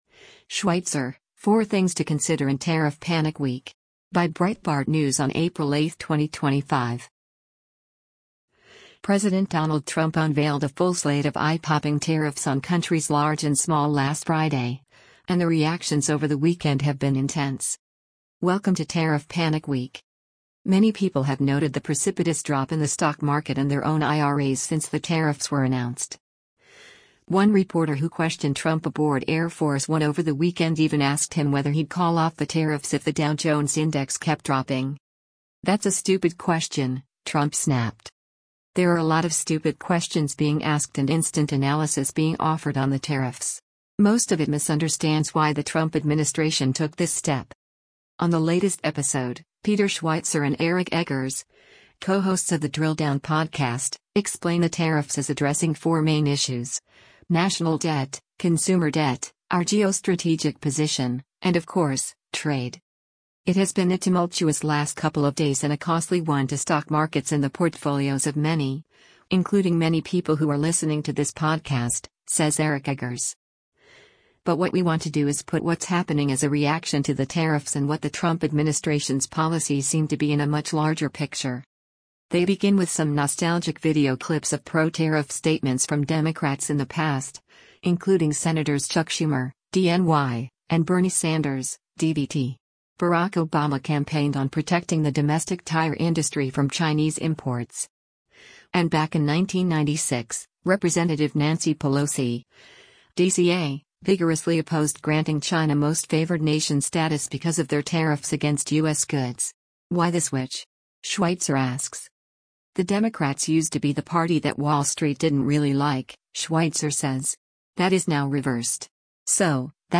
They begin with some nostalgic video clips of pro-tariff statements from Democrats in the past, including Sens. Chuck Schumer (D-NY) and Bernie Sanders (D-VT).